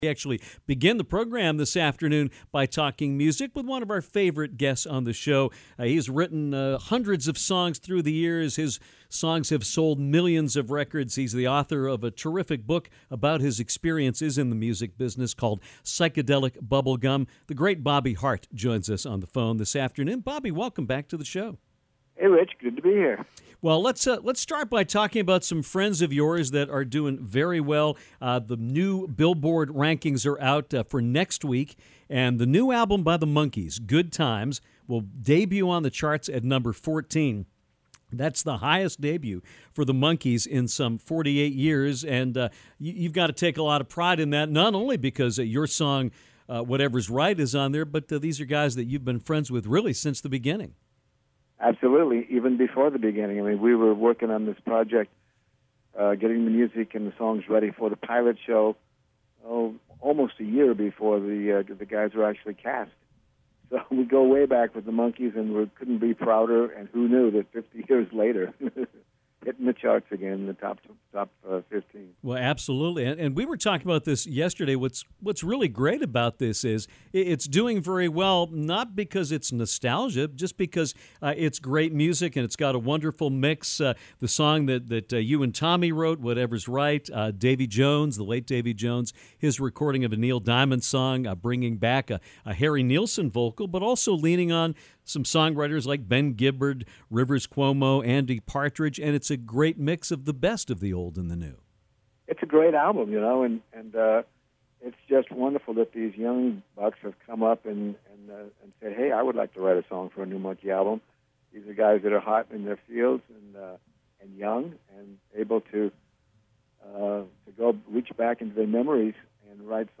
Songwriter extraordinaire Bobby Hart joined the program once again to discuss his involvement in the brand new Monkees album, which includes a song written with his late partner, Tommy Boyce, and Bobby also harkened back to writing the songs for them before they had all been cast. Bobby is a gem and has stories for days; hear a bunch of them in this interview.